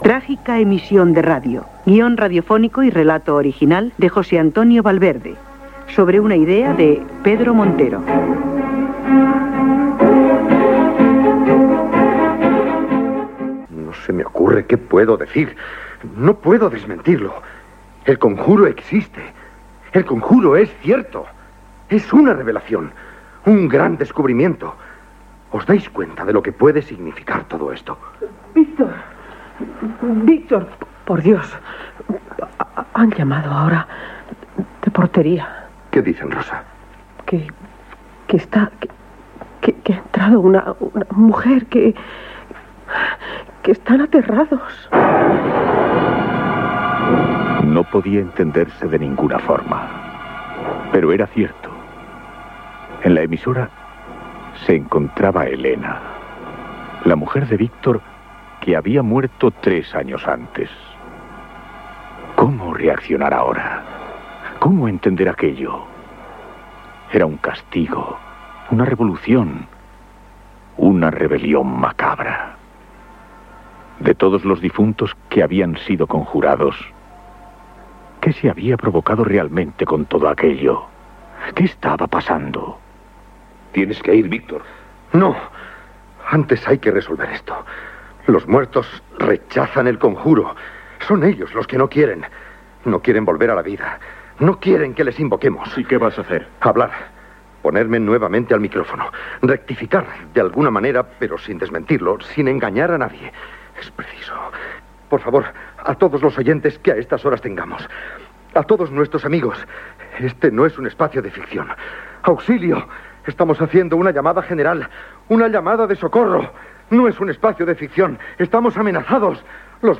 Emissió de l'obra de misteri i terror "Trágica emisión de radio".
Ficció